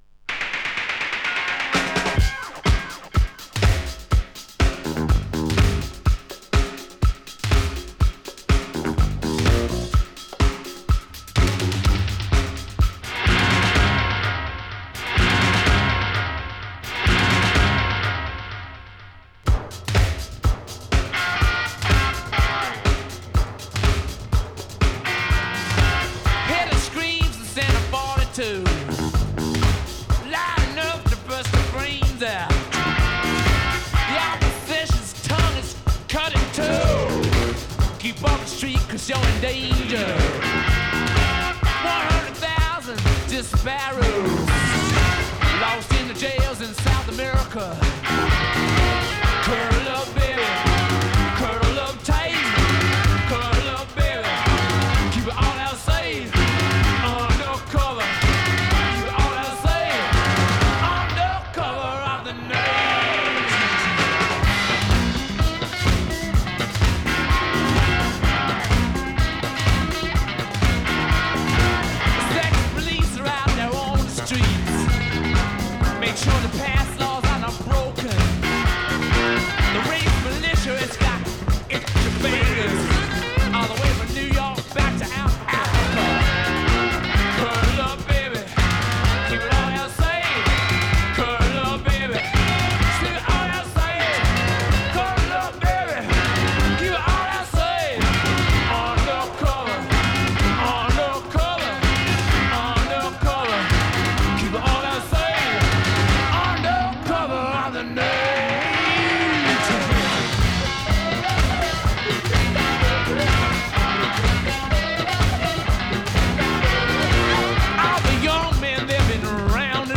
Ze zijn trouwens niet mono, maar stereo.